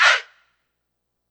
SouthSide Chant (25)(1).wav